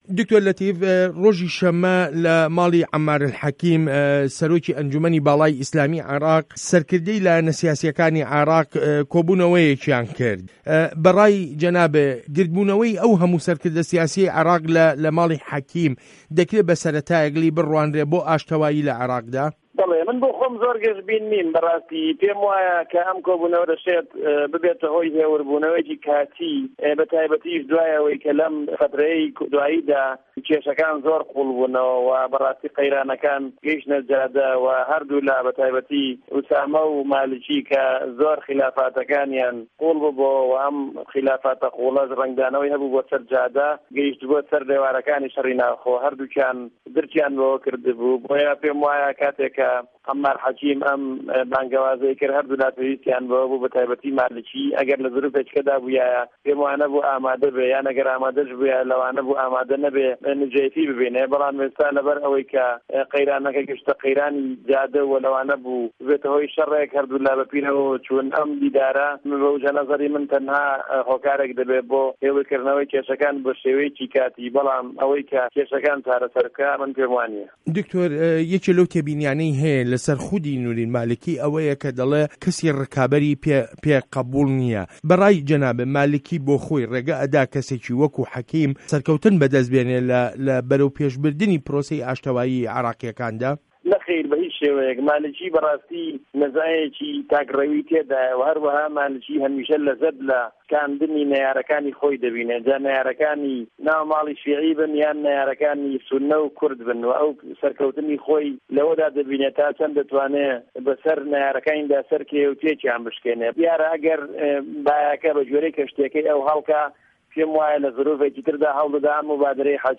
وتووێژ له‌گه‌ڵ دکتۆر له‌تیف موسته‌فا